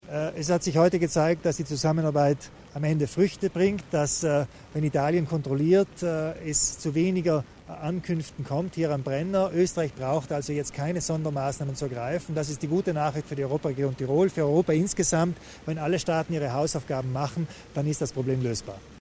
Landeshauptmann Kompatscher betont die Wichtigkeit der Zusammenarbeit zwischen Italien und Österreich